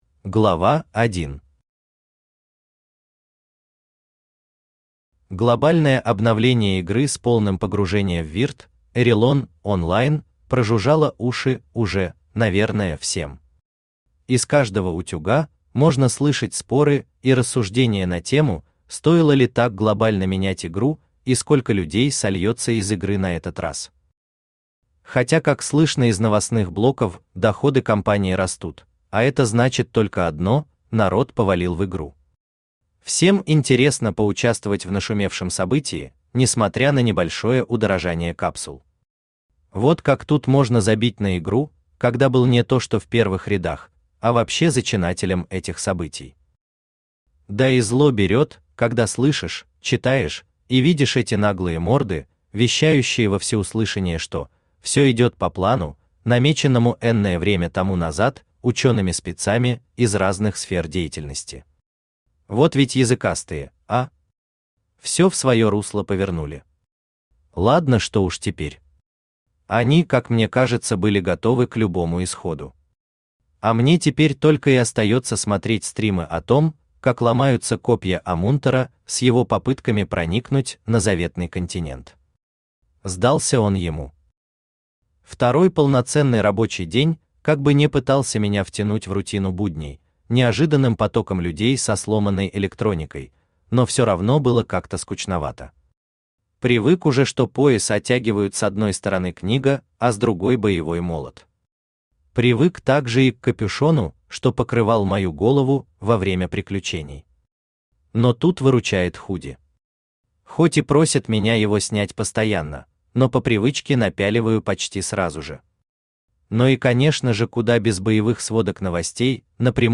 Книга третья Автор Игорь Углов Читает аудиокнигу Авточтец ЛитРес.